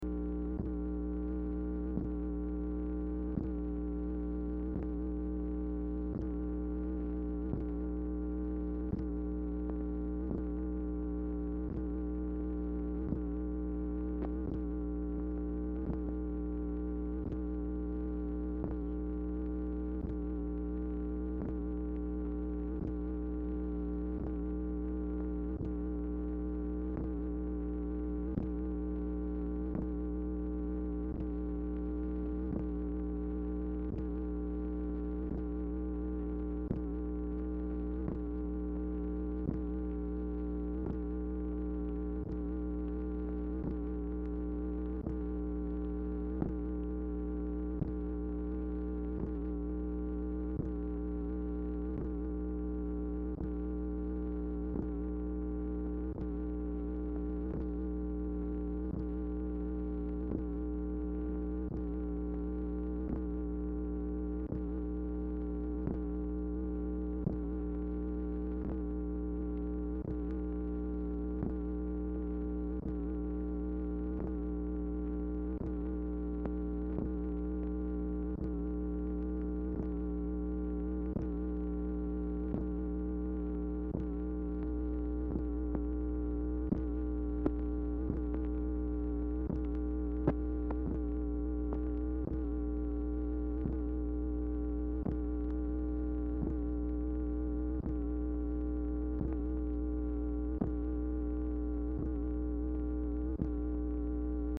Telephone conversation # 2071, sound recording, MACHINE NOISE, 2/12/1964, time unknown | Discover LBJ
Format Dictation belt
White House Telephone Recordings and Transcripts Speaker 2 MACHINE NOISE